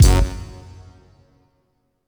GUnit Synth11.wav